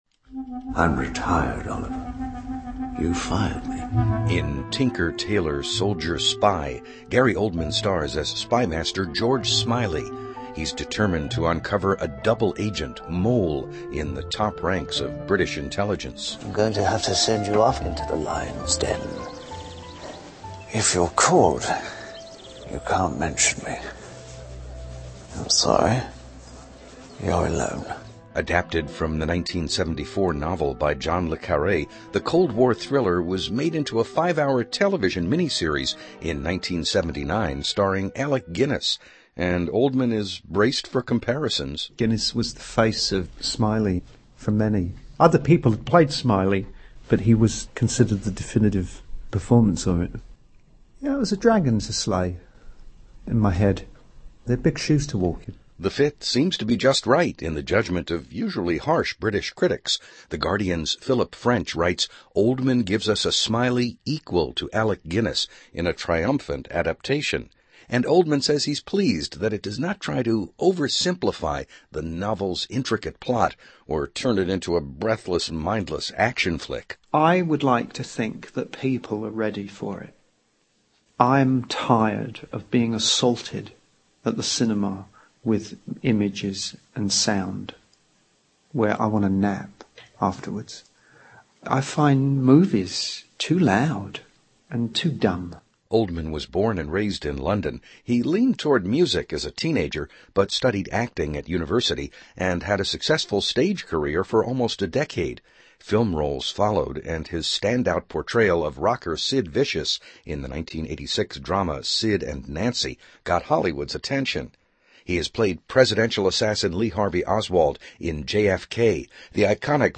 interview with actor Gary Oldman